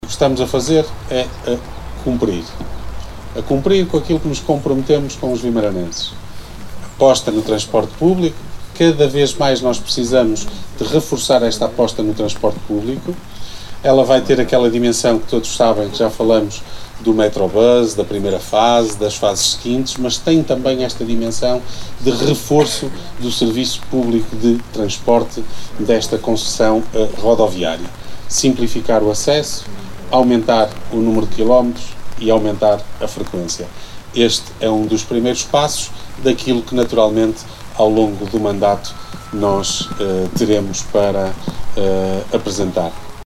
Ora, por seu lado, o presidente da Câmara, Ricardo Araújo, realça que está a cumprir o que foi prometido à comunidade.